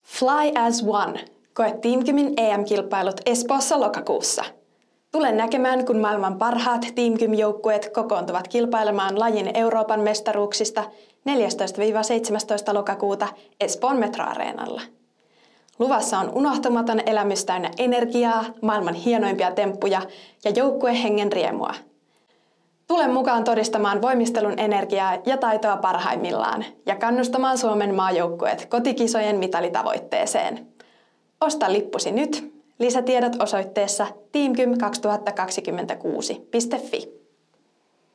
TeamGymin EM-kisat äänimainos - Materiaalipankki
TeamGymin-EM-kisat-aanimainos.mp3